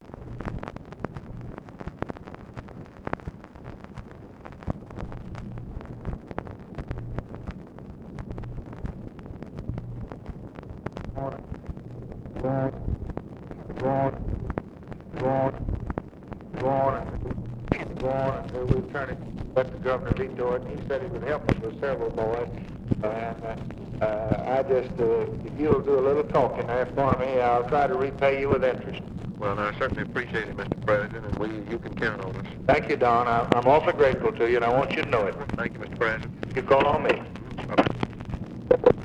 Conversation with DON FUQUA, August 5, 1964
Secret White House Tapes